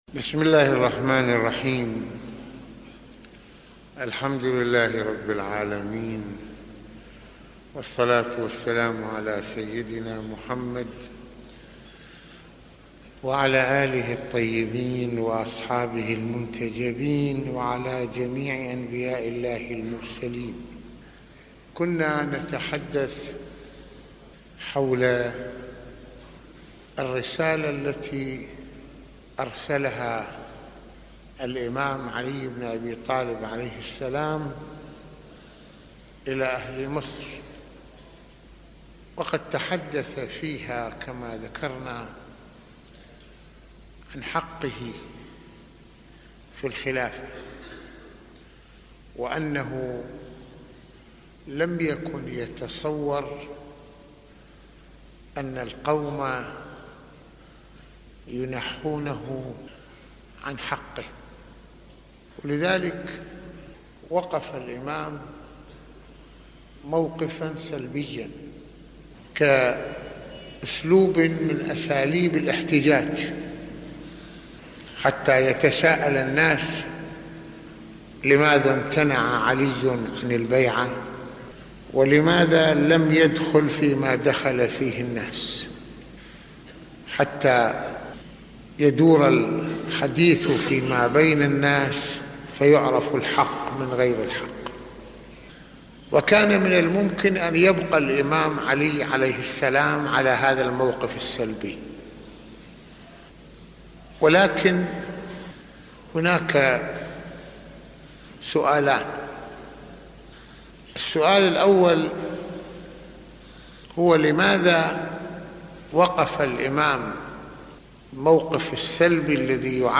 - يتحدث المرجع السيد محمد حسين فضل الله (رض) في هذه المحاضرة عن رسالة أمير المؤمنين (ع) لأهل مصر وموقفه من الخلافة وما أراد للناس أن يفهموه من حق له ، ويتناول ما جسّده عليٌ من حرص على مصلحة الإسلام ، وما مثّله علي من شخصية رسالية ربانية أخلصت لربها وللإسلام الأصيل ...